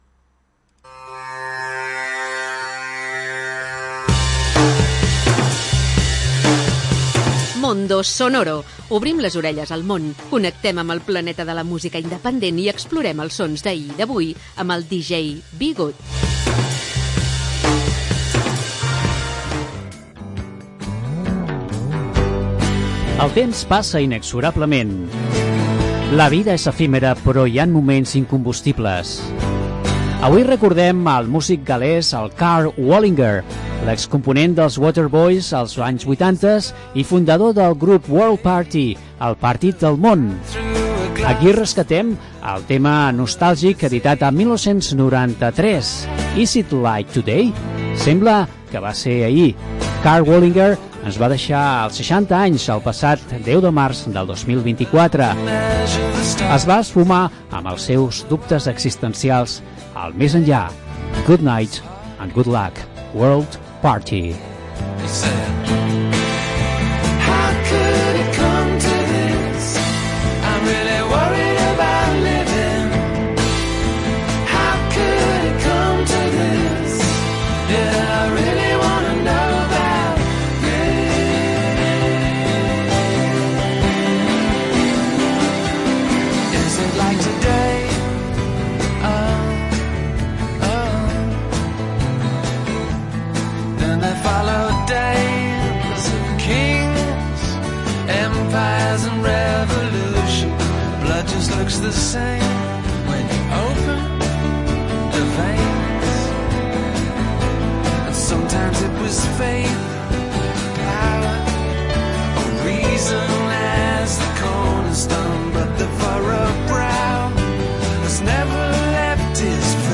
Una selecció de música amb esperit independent